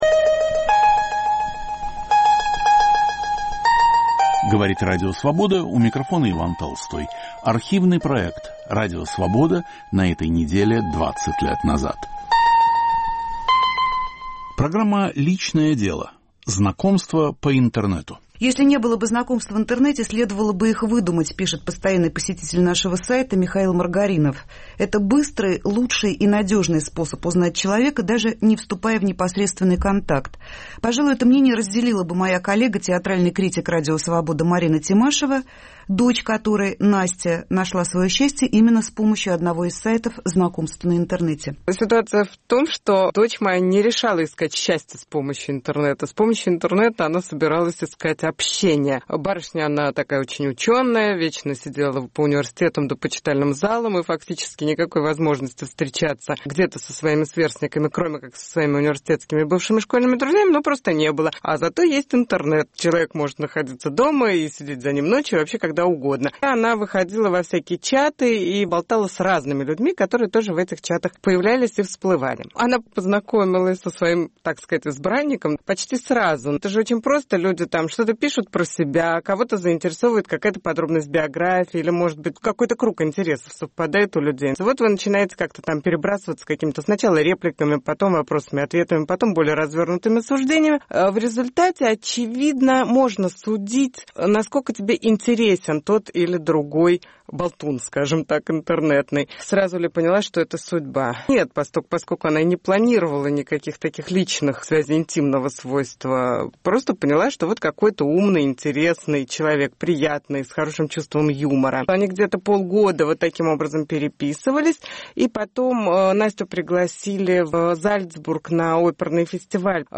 слушатели в прямом эфире и эксперты - психолог, специалист по семантическому анализу текста, активный посетитель сайтов знакомств - обмениваются мнениями по поводу плюсов и минусов интернет-общения.